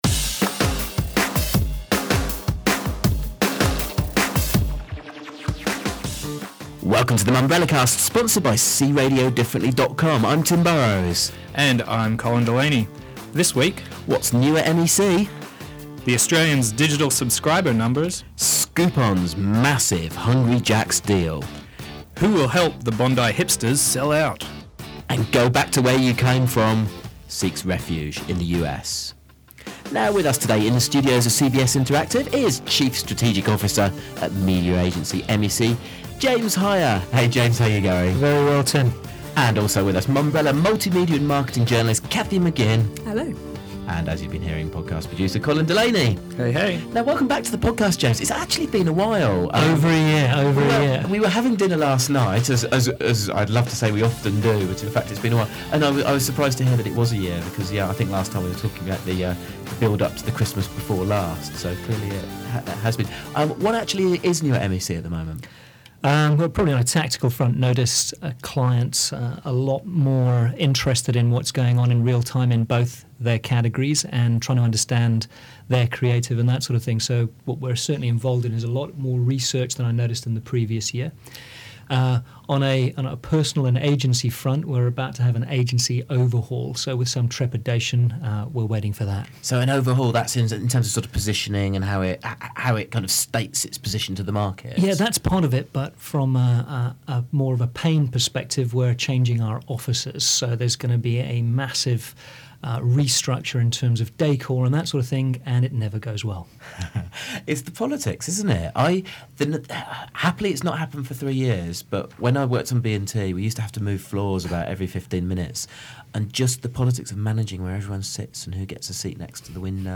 Apologies for some dodgy sound levels, particularly at the beginning.